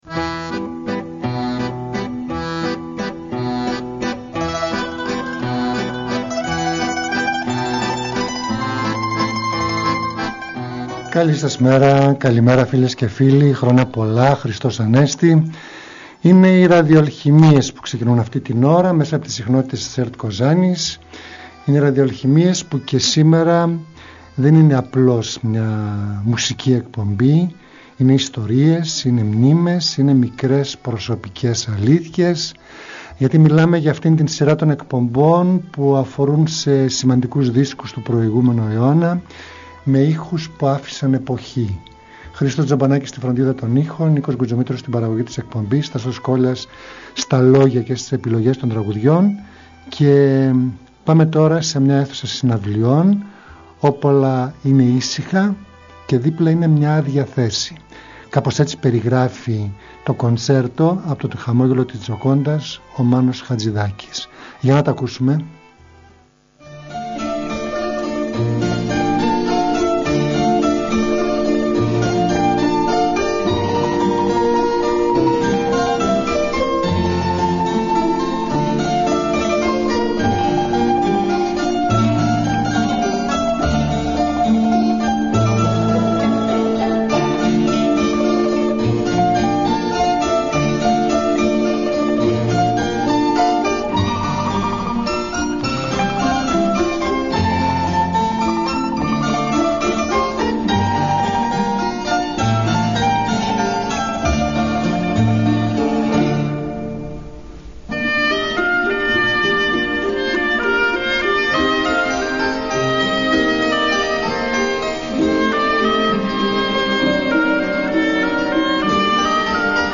Μια εκπομπή μουσικής και λόγου διανθισμένη με επιλογές από την ελληνική δισκογραφία.